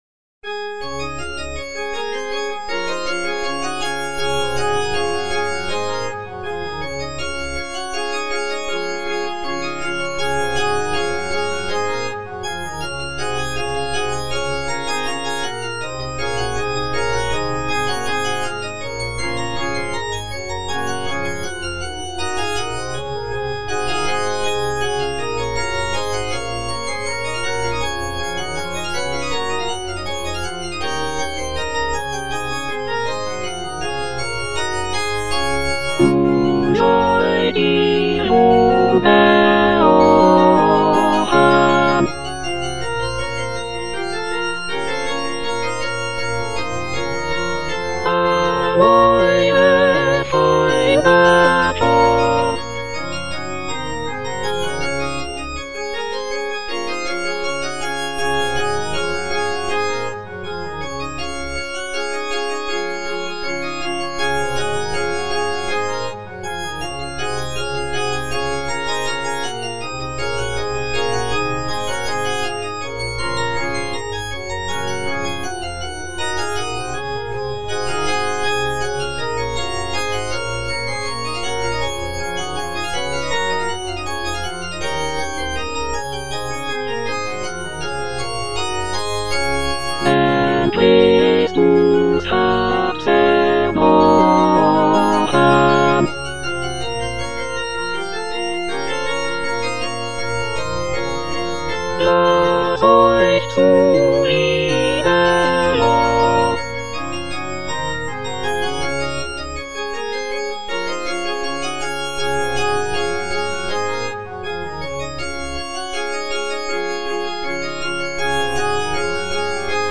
(A = 415 Hz)
Soprano (Emphasised voice and other voices) Ads stop